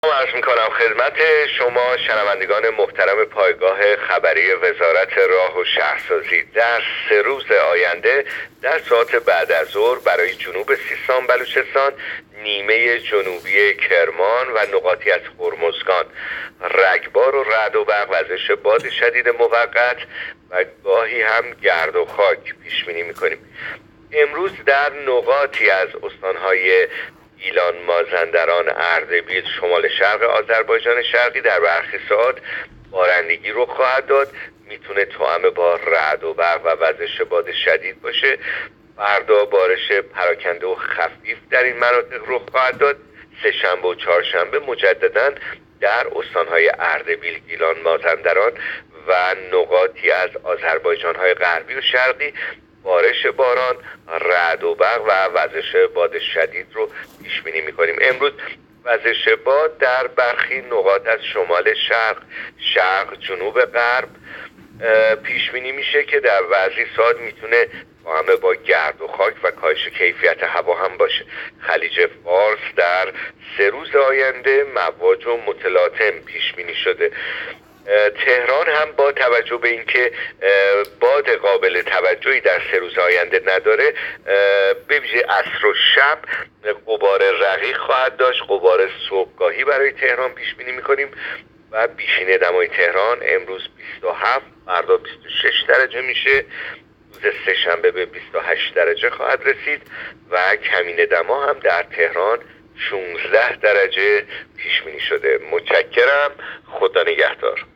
گزارش رادیو اینترنتی پایگاه‌ خبری از آخرین وضعیت آب‌وهوای ۲۶ مهر؛